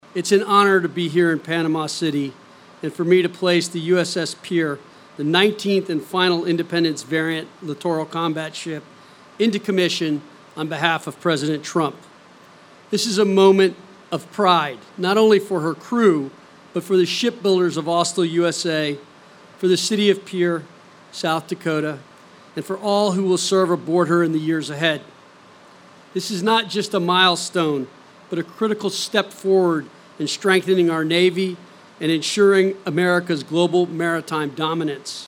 PANAMA CITY, F.L.(KCCR)- The U-S-S Pierre officially joined the fleet of the United States Navy Saturday morning with a traditional Commissioning Ceremony at Port Panama City Florida.
United States Navy Secretary John Phelan says the ceremony is more than just naval tradition.